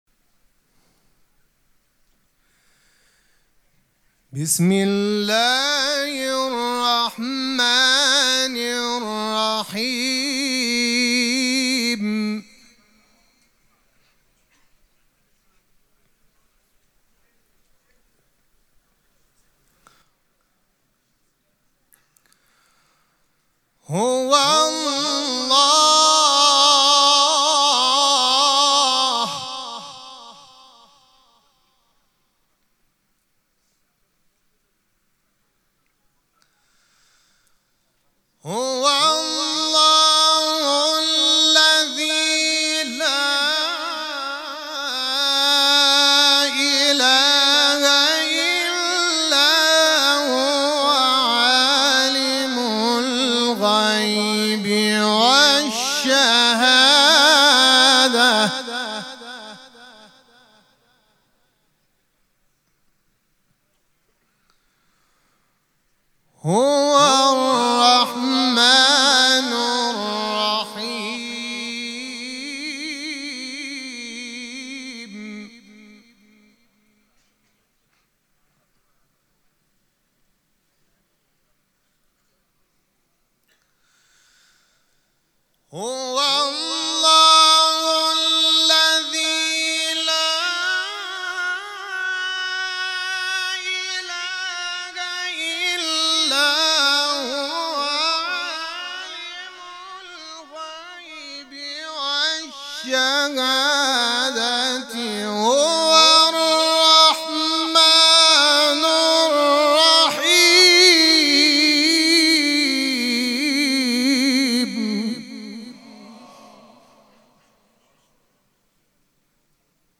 مراسم مناجات شب بیست و سوم ماه مبارک رمضان
حسینیه ریحانه الحسین سلام الله علیها
قرائت قرآن